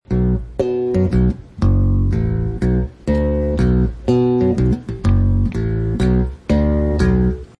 plays short on the bassline